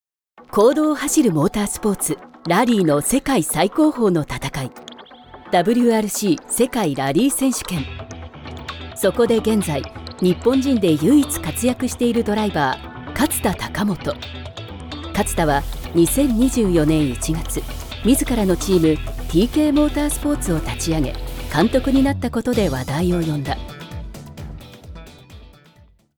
Automobile
Sa voix douce, claire et attachante est très polyvalente, ce qui en fait le choix incontournable de nombreuses grandes entreprises et organismes gouvernementaux.